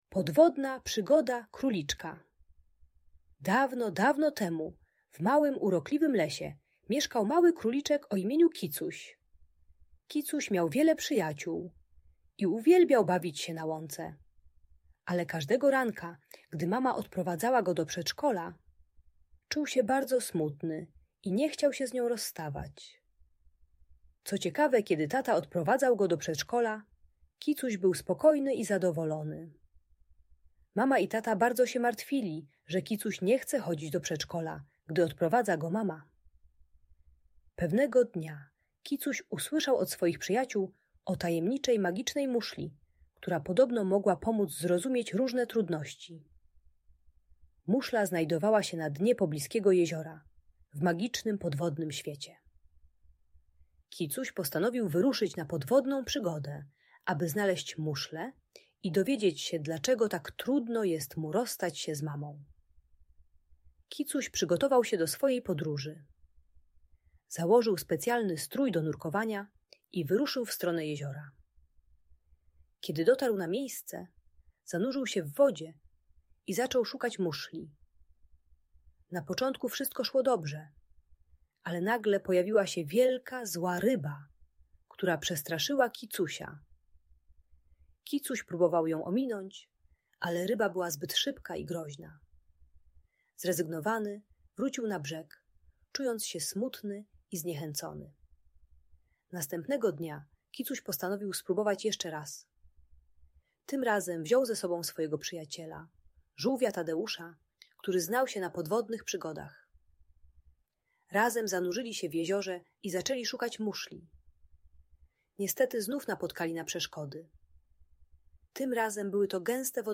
Podwodna Przygoda Króliczka - Audiobajka